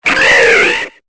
Cri de Gueriaigle dans Pokémon Épée et Bouclier.